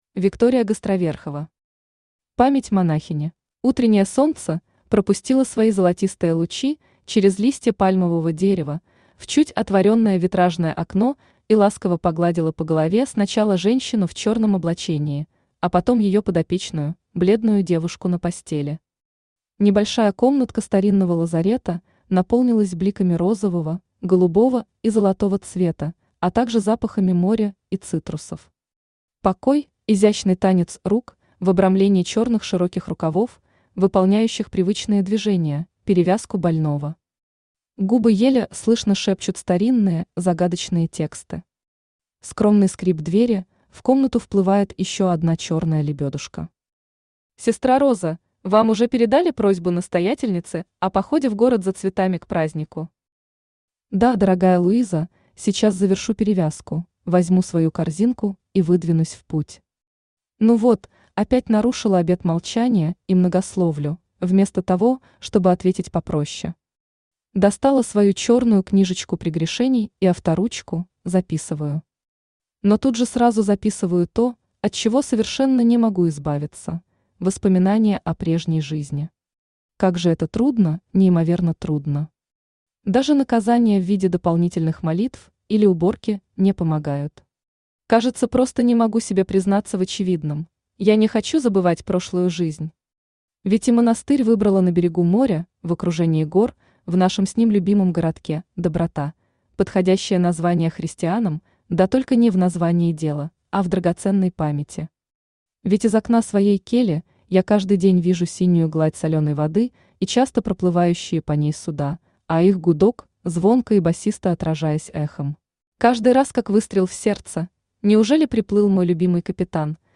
Aудиокнига Память монахини Автор Виктория Гостроверхова Читает аудиокнигу Авточтец ЛитРес.